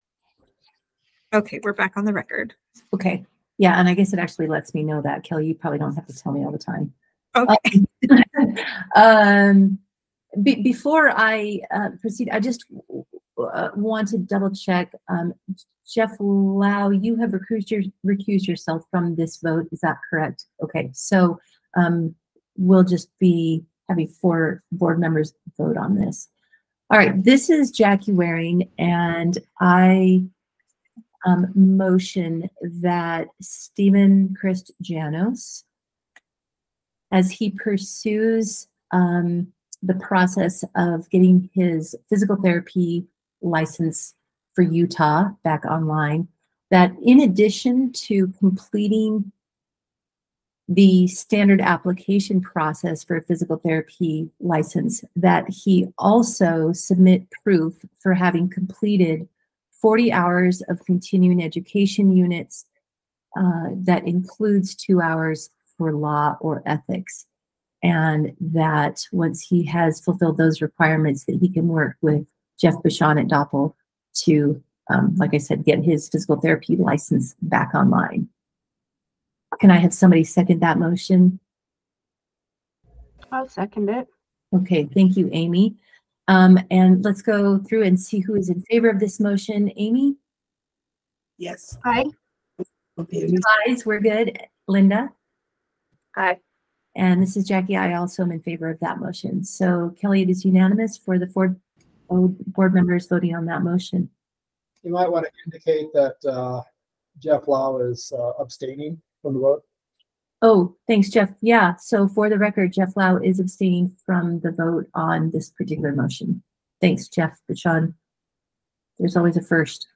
Meeting
Electronic participation is planned for this meeting.